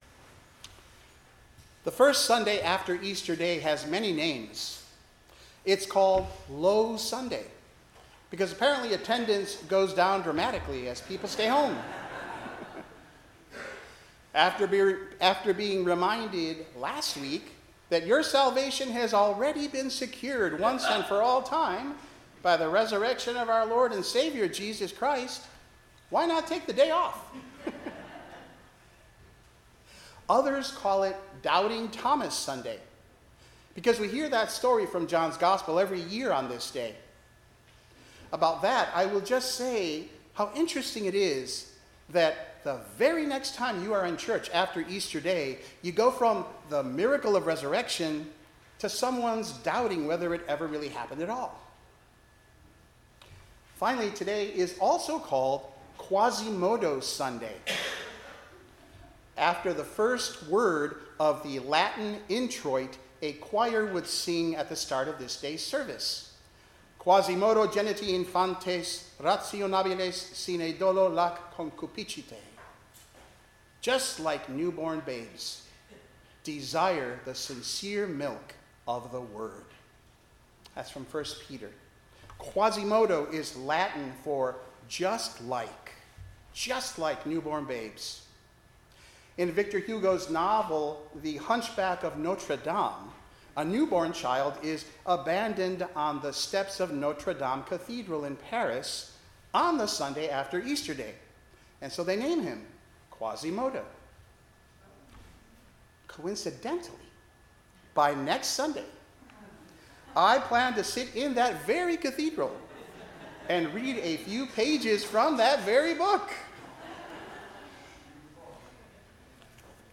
10:00 am Service